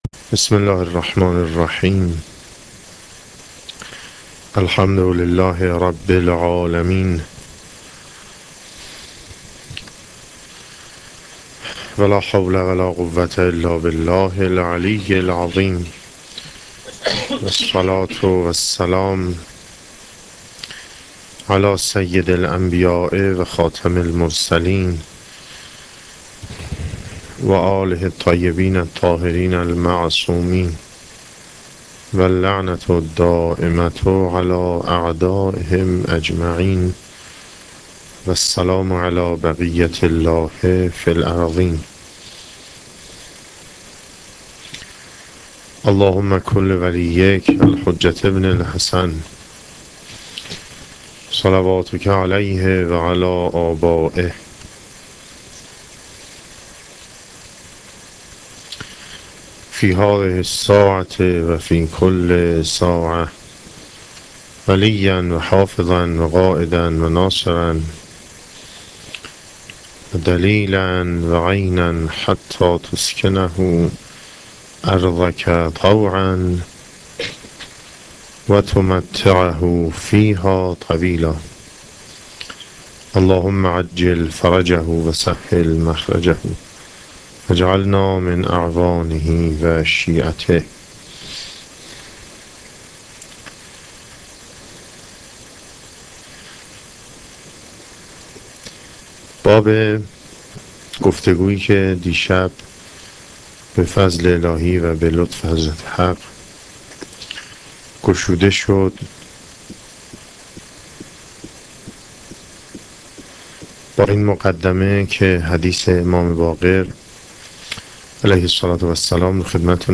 سخنرانی شب دوم